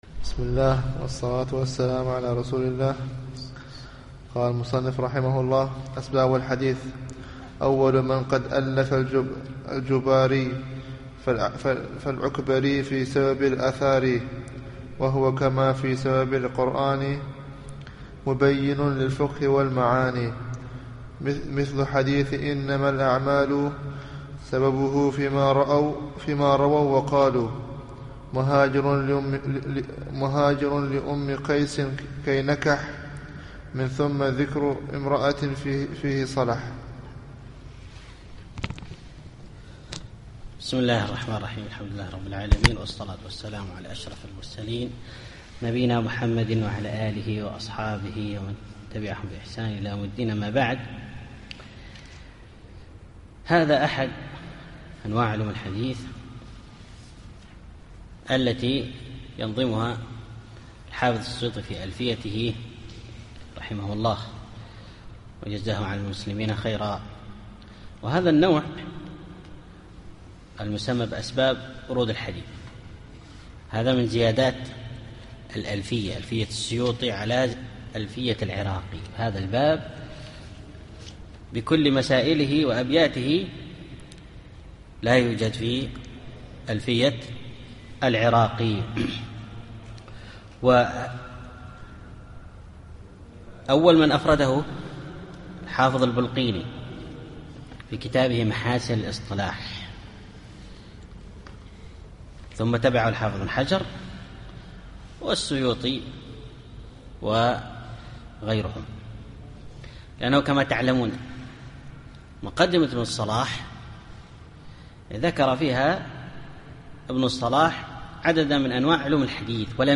الدرس التاسع والعشرون